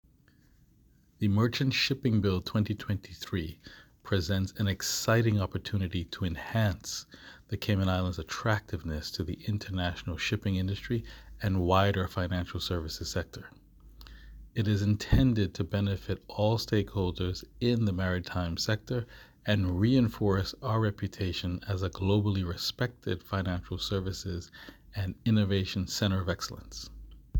MinistersMerchantShippingQuote-6November2023.m4a